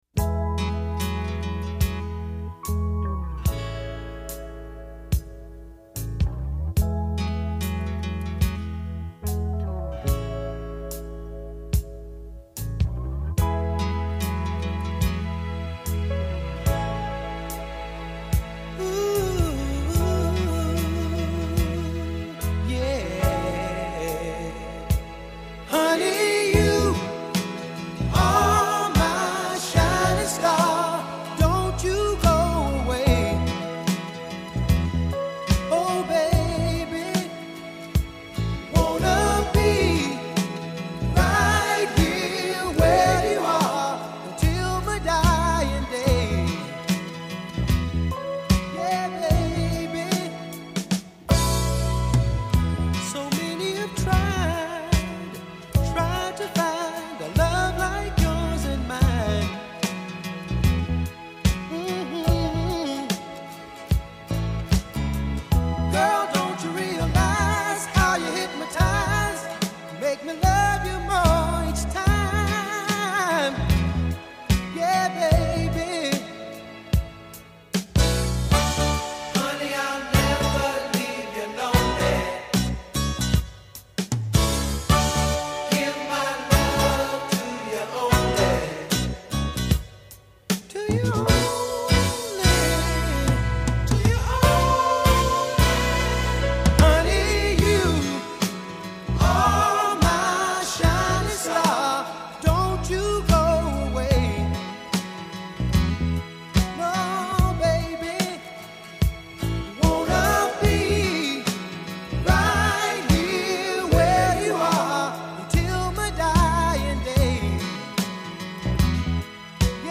smooth ’70s soul written all over it